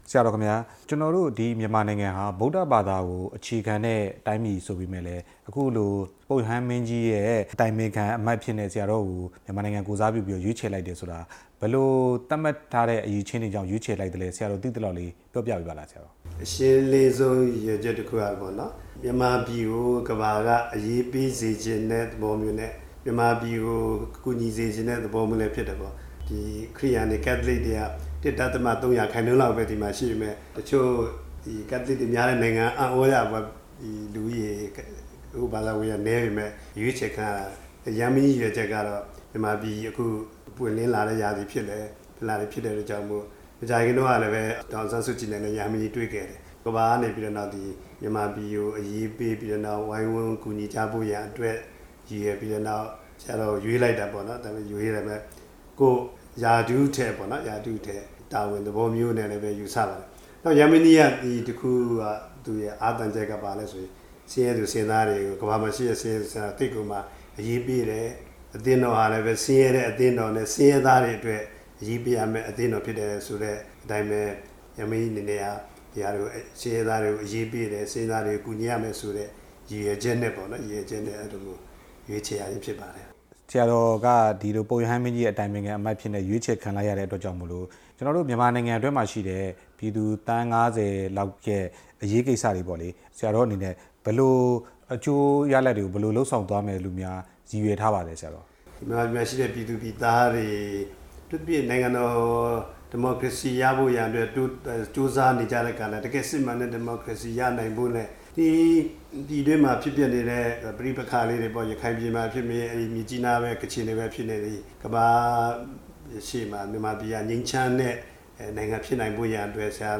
ရန်ကုန်ဂိုဏ်းချုပ် သာသနာ ဆရာတော် ချားလ်ဘိုနဲ့ မေးမြန်းချက်